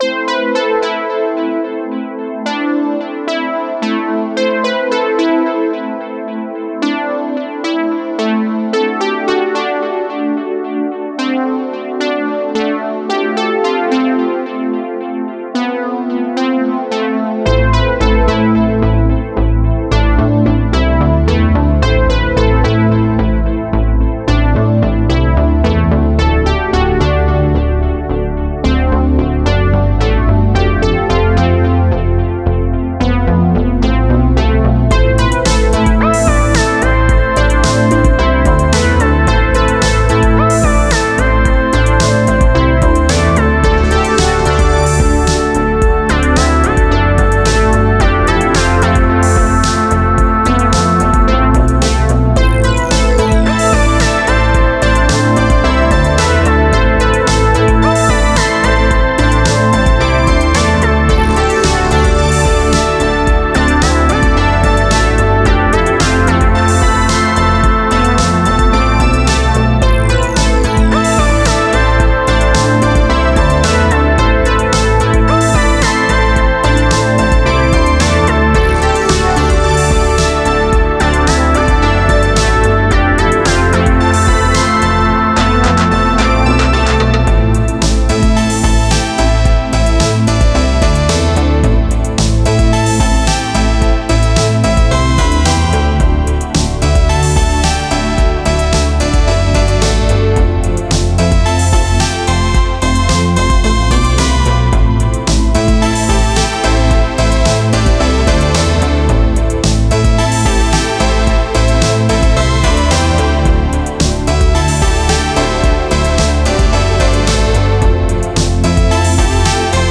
Жанр: Synthwave